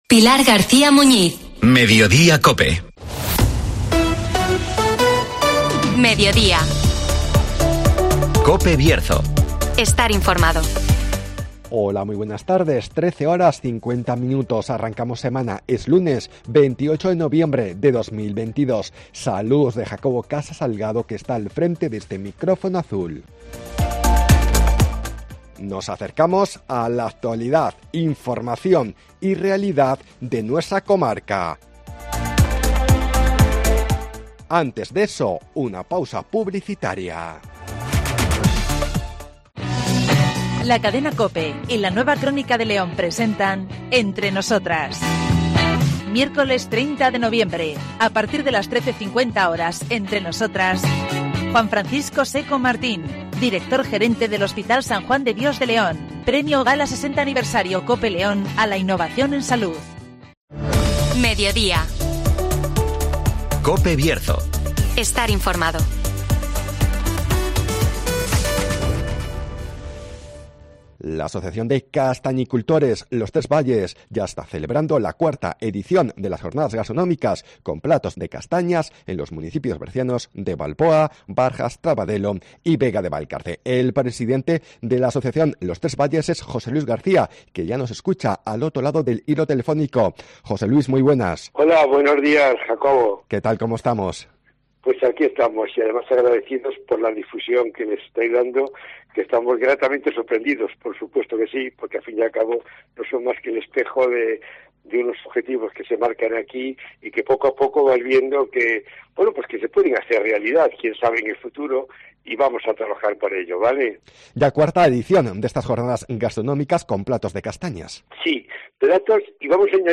La asociación Tres Valles organiza las IV jornadas gastronómicas con platos de castañas (Entrevista